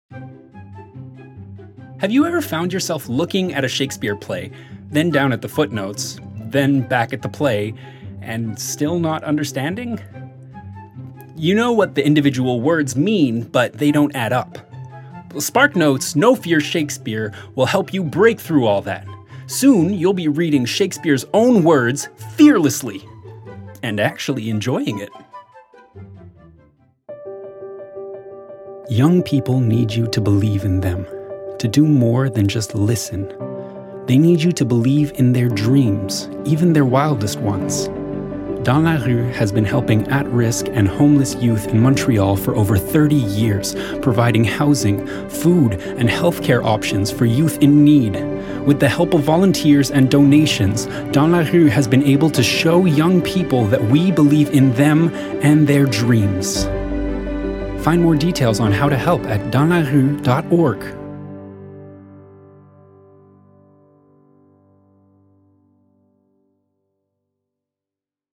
Démo voix - ANG